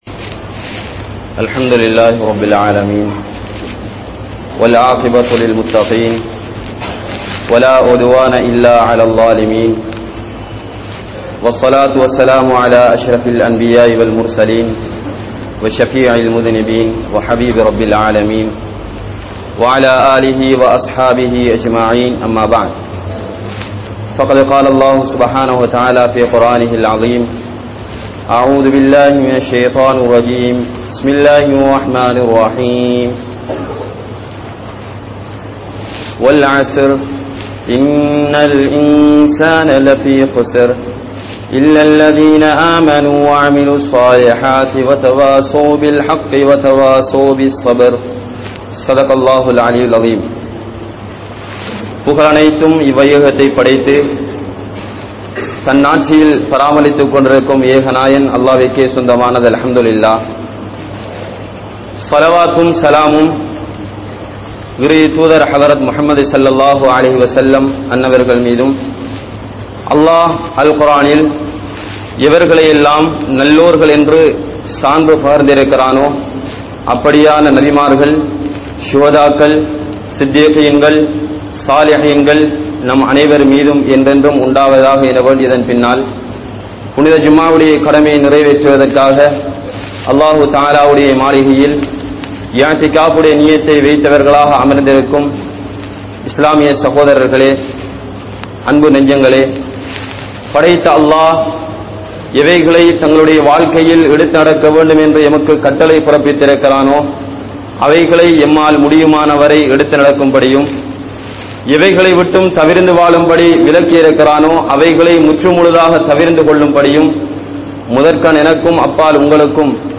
Islam Koorum Ramalan (இஸ்லாம் கூறும் ரமழான்) | Audio Bayans | All Ceylon Muslim Youth Community | Addalaichenai